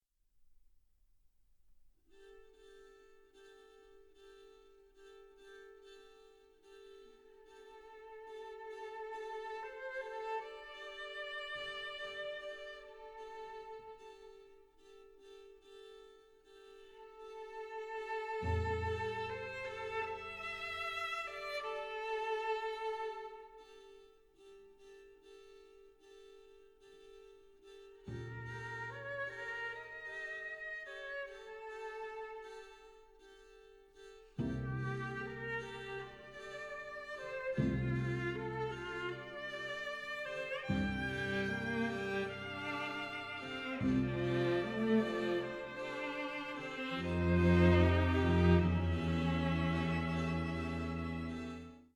violin
viola
cello
Recorded in April 2011 at Potton Hall, Suffolk, UK